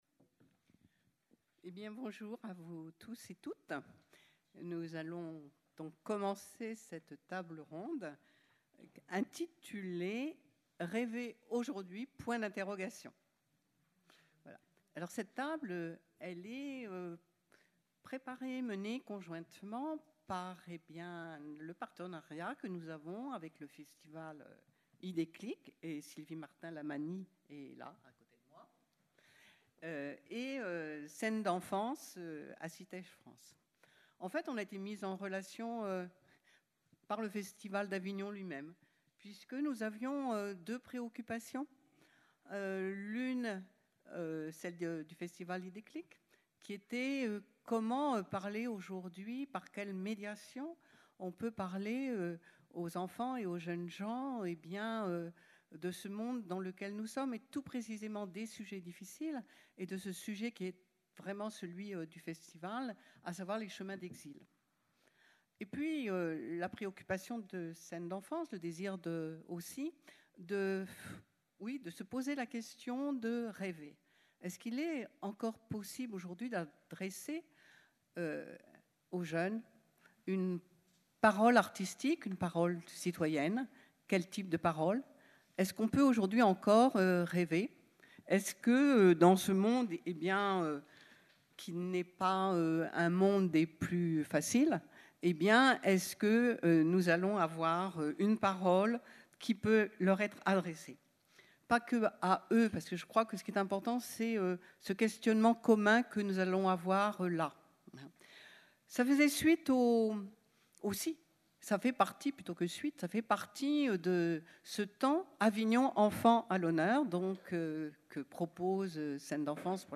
Écouter la captation de la rencontre :
Rêver aujourd’hui ? Table ronde
Lundi 15 juillet à 16h00 – Cloître Saint Louis